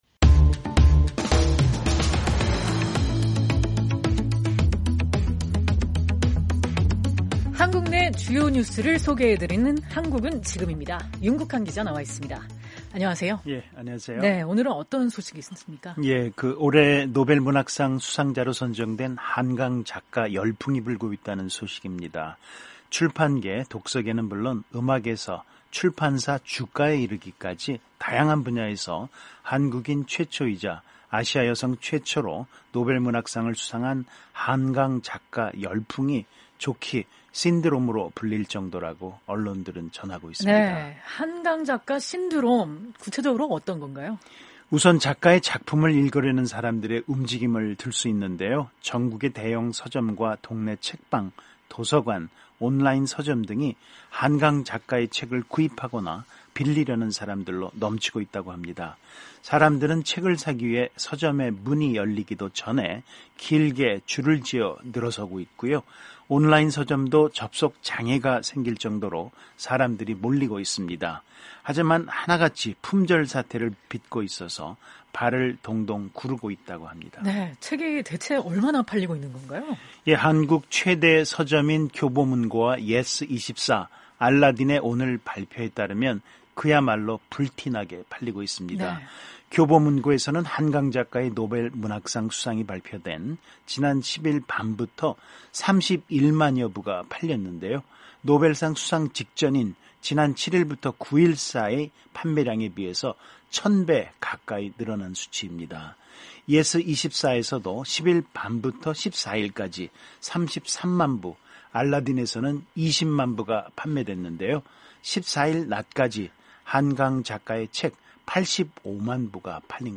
한국 내 주요 뉴스를 전해 드리는 `한국은 지금’ 입니다.